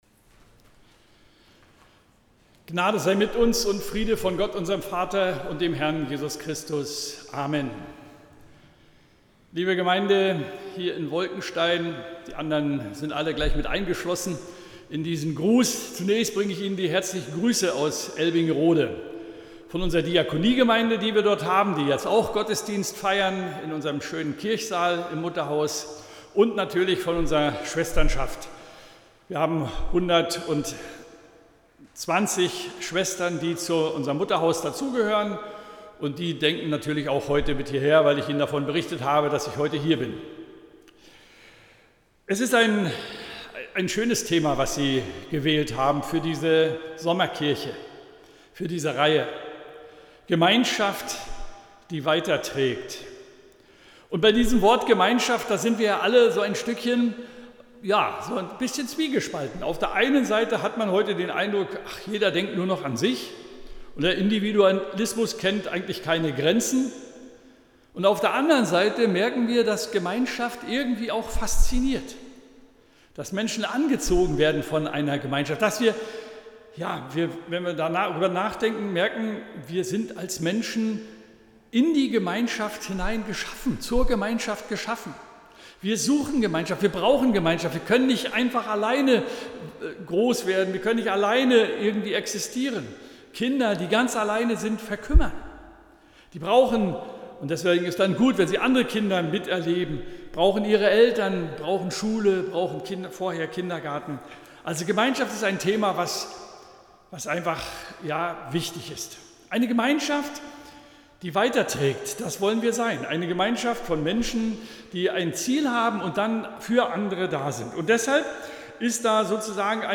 15.08.2021 – Gottesdienst
Predigt und Aufzeichnungen